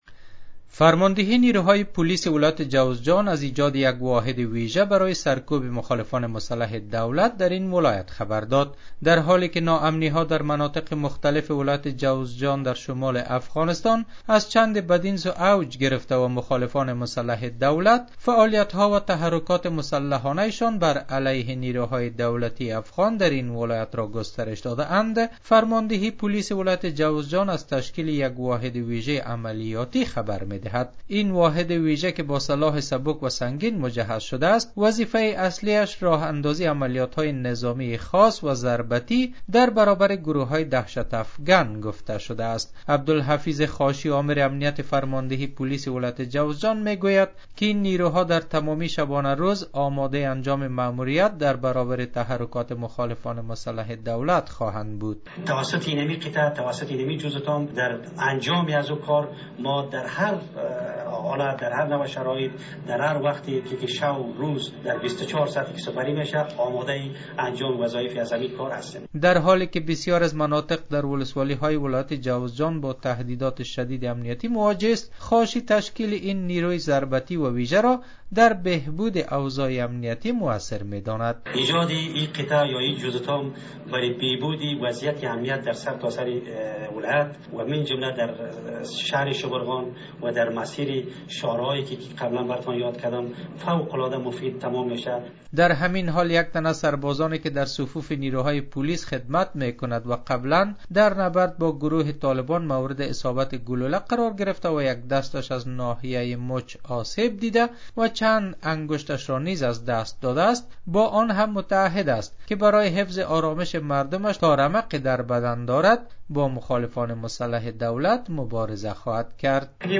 گزارش : تشکیل نیروهای ویژه پلیس در ولایت جوزجان برای مبارزه با مخالفان